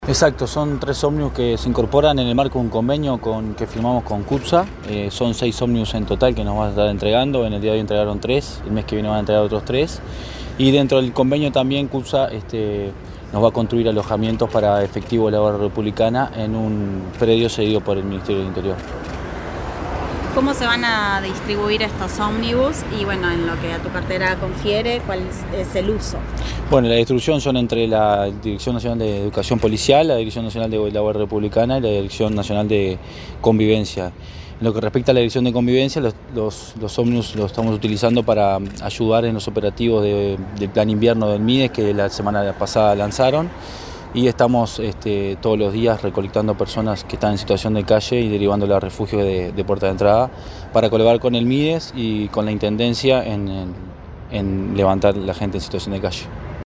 Declaraciones del director de Convivencia y Seguridad Ciudadana, Matías Terra
El director de Convivencia y Seguridad Ciudadana del Ministerio del Interior, Matías Terra, dialogó con la prensa, este miércoles 22 en Montevideo,